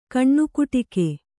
♪ kaṇṇukuṭike